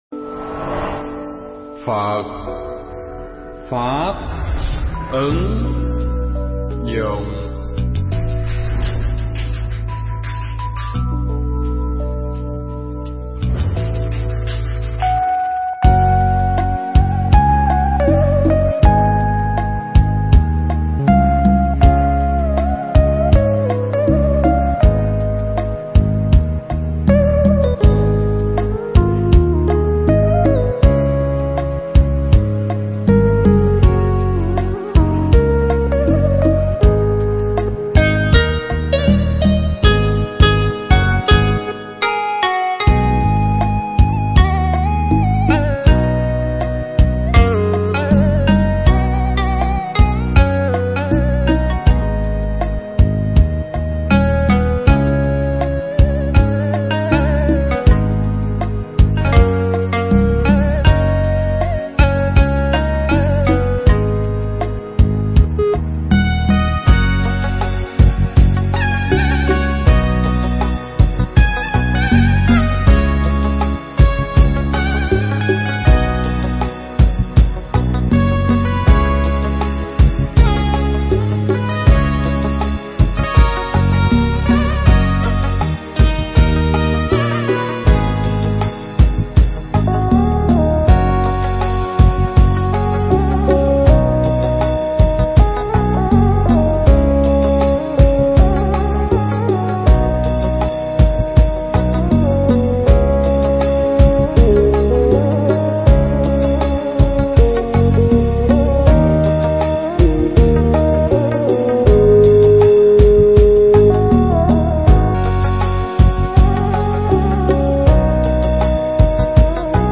Bài pháp thoại
thuyết giảng tại chùa Hoa Nghiêm (Bỉ Quốc)